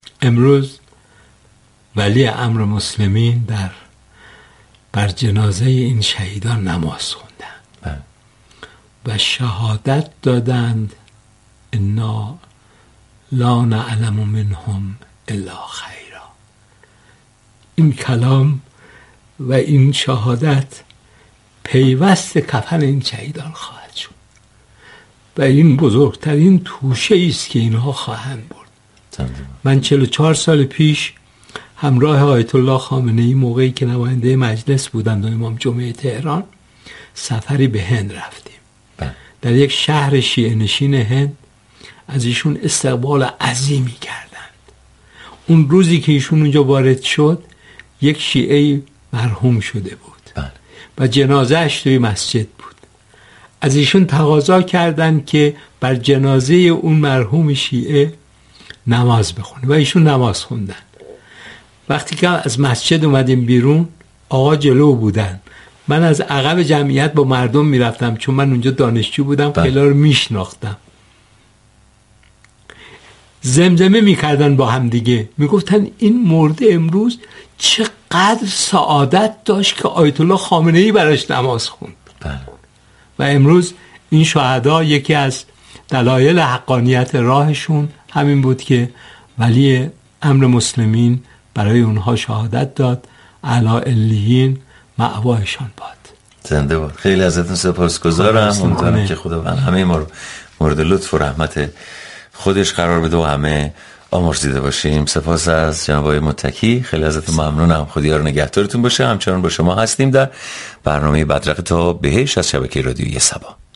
منوچهر متكی وزیر پیشین امور خارجه مهمان رادیو صبا شد